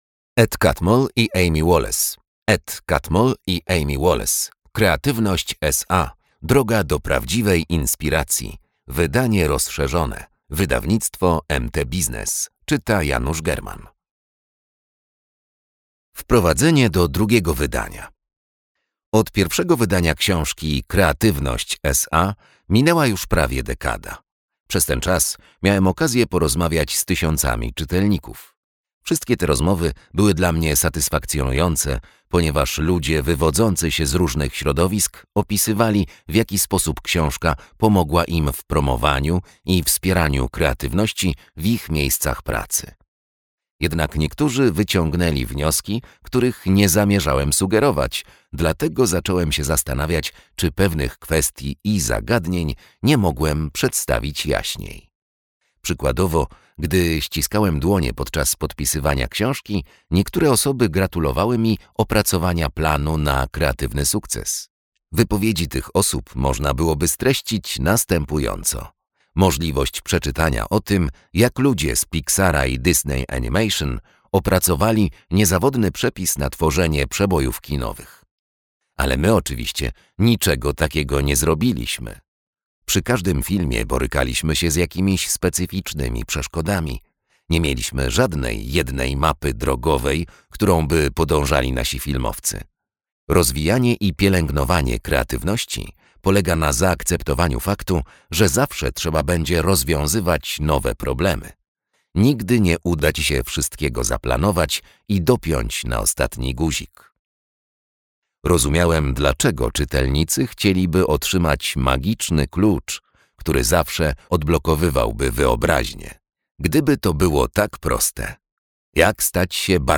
Kreatywność S.A. Droga do prawdziwej inspiracji. WYDANIE ROZSZERZONE - Ed Catmull, Amy Wallace - audiobook